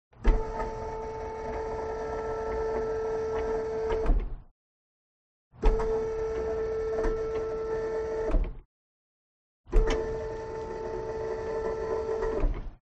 Звуки автосервиса
Шум автоподъемника